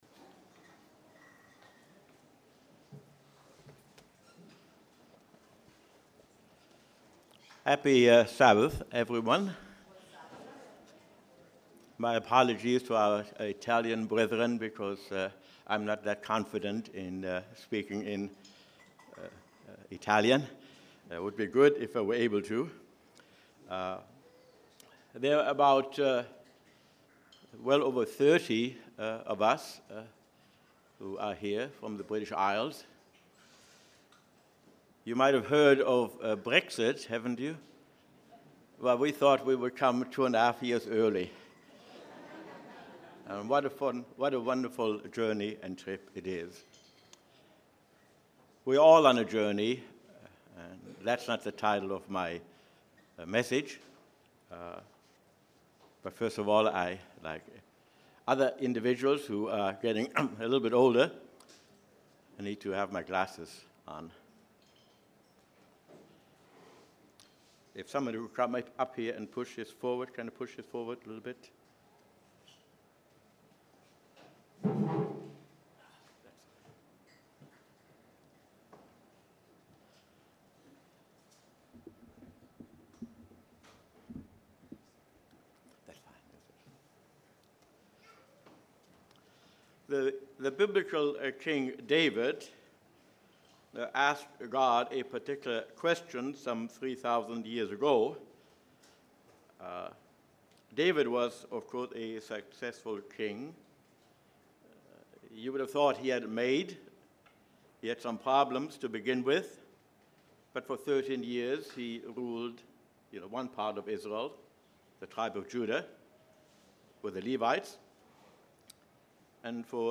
English message